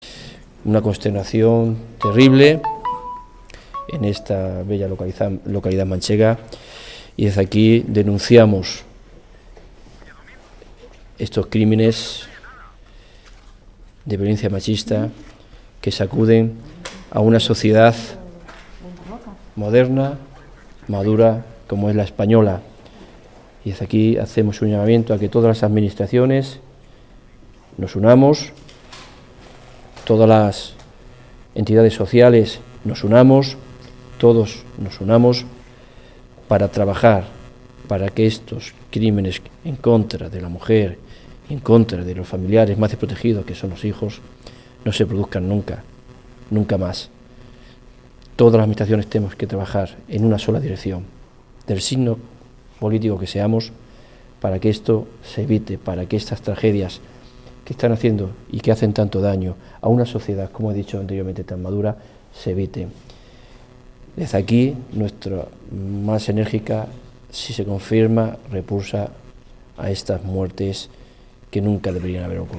Declaraciones Delegado del Govierno en CLM 2